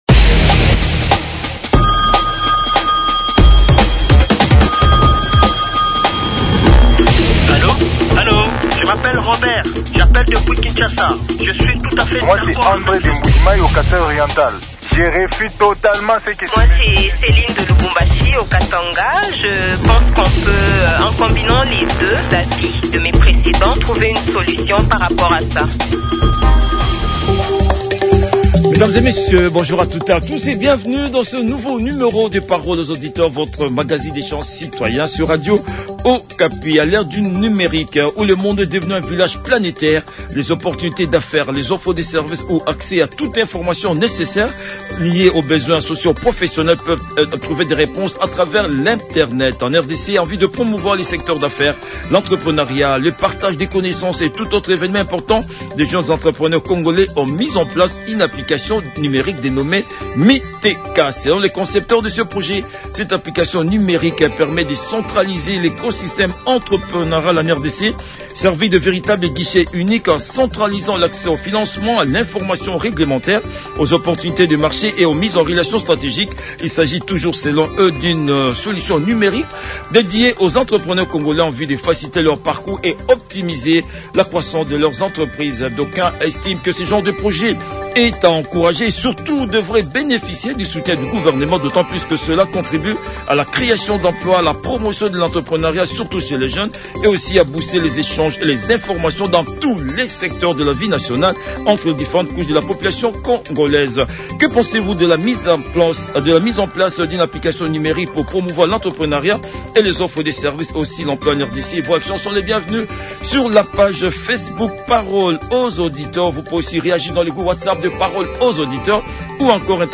L’échange citoyen s’est déroulé entre les auditeurs